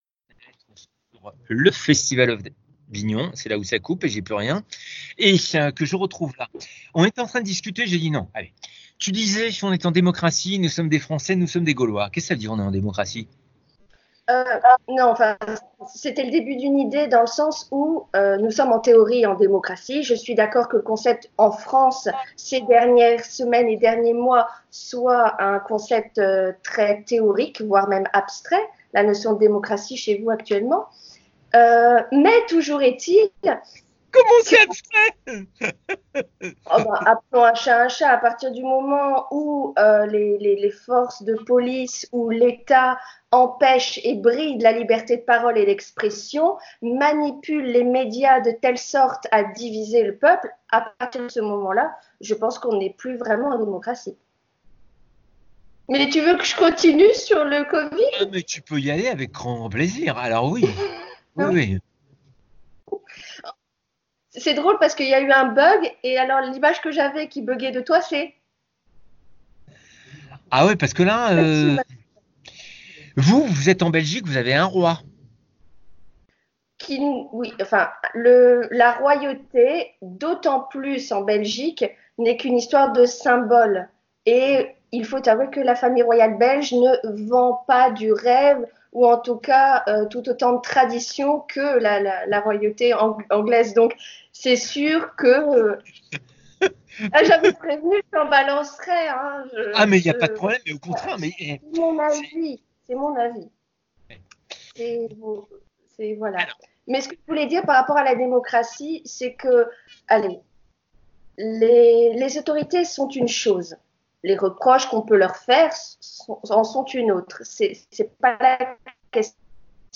en direct de Mouscron, Belgique - Direct en Jeu